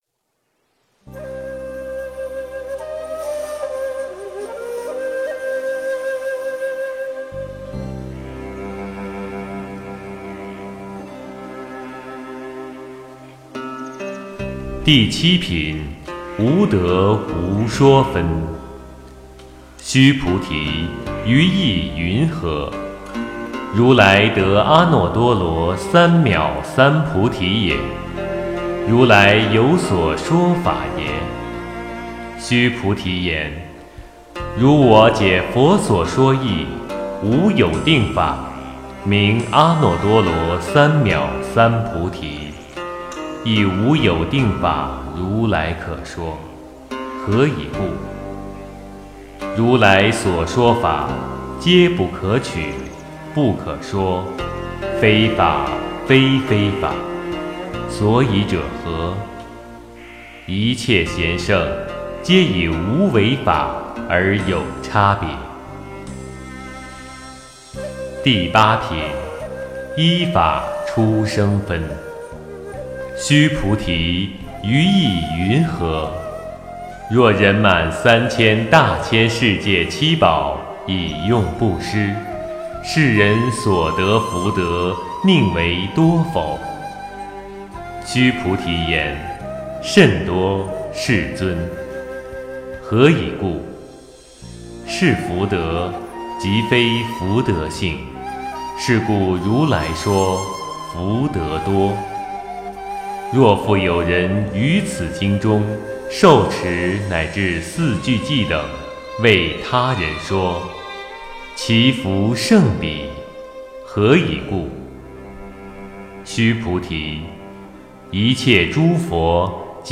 诵经
佛音 诵经 佛教音乐 返回列表 上一篇： 《妙法莲华经》见宝塔品第十一 下一篇： 金刚经：第十九品和第二十品 相关文章 龙朔操--古琴 龙朔操--古琴...